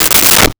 Toilet Seat Fall 02
Toilet Seat Fall 02.wav